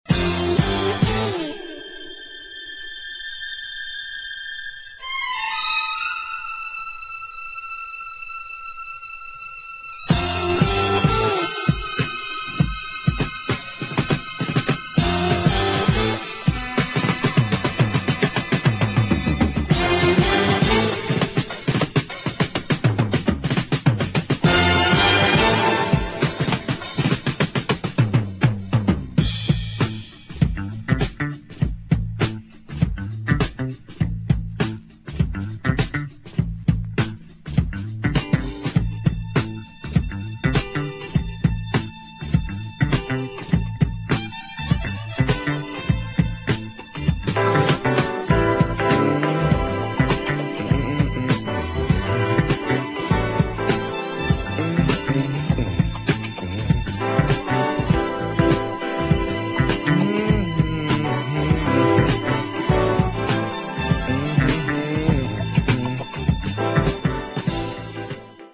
[ JAZZ / FUNK / DOWNBEAT ]